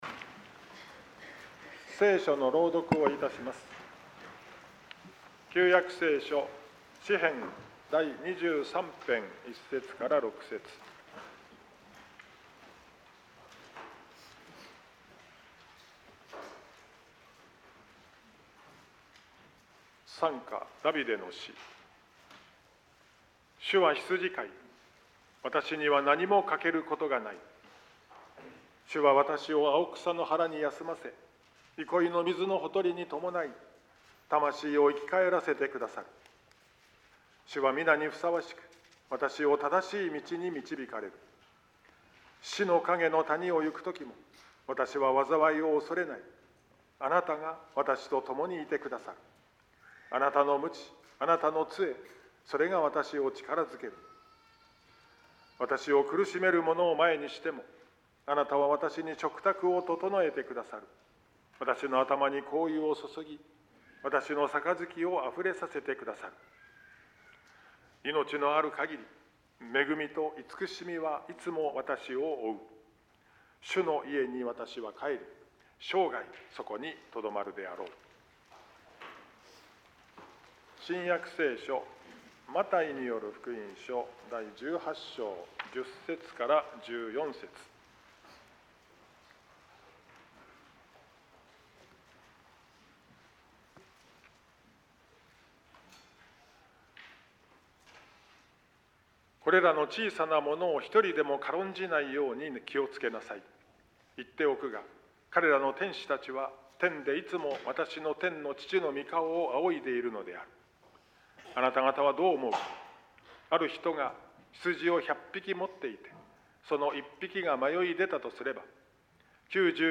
説教